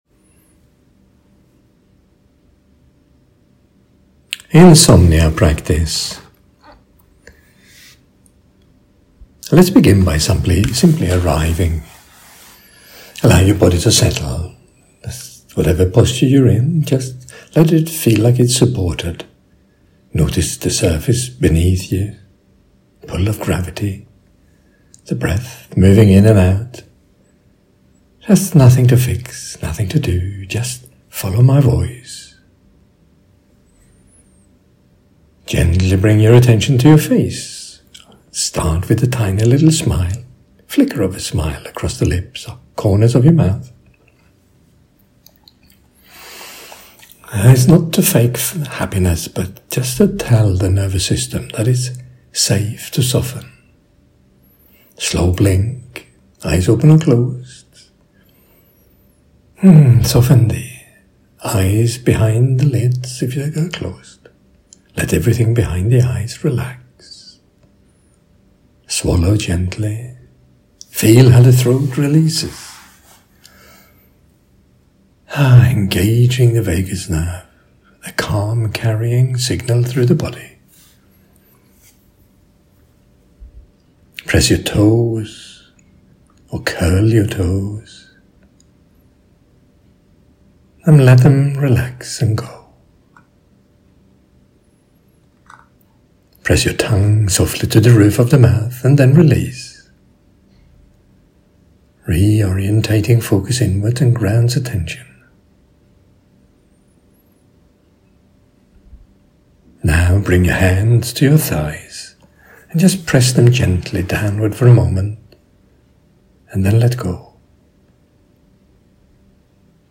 I have recorded a free 7-minute Sleep-Down Practice blending MMPM and cognitive shuffling.